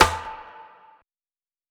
Percs